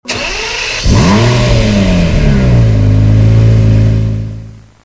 Home gmod sound vehicles tdmcars murcielago
enginestart.mp3